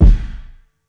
Hayes Kick 3k.wav